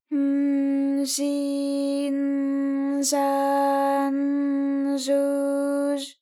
ALYS-DB-001-JPN - First Japanese UTAU vocal library of ALYS.
j0_n_j0i_n_j0a_n_j0u_j0.wav